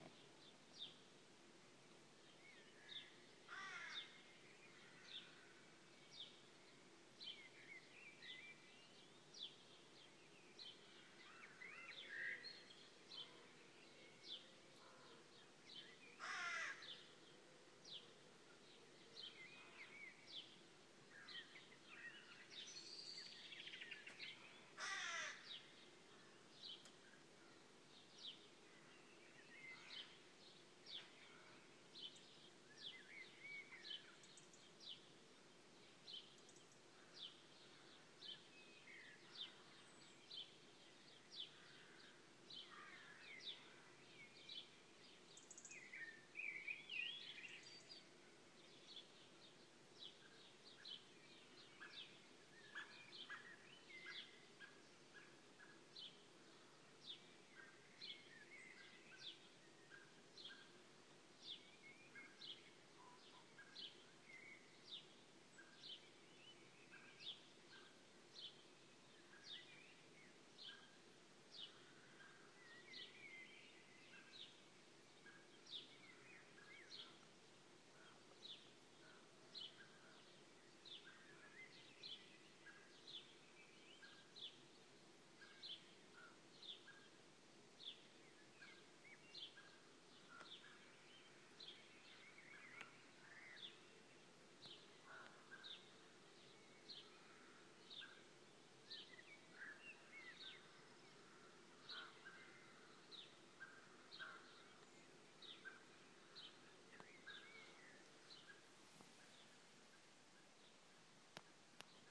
0430 Alarm call Nature calling!